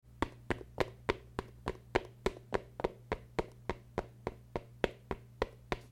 Running Feet Téléchargement d'Effet Sonore
Running Feet Bouton sonore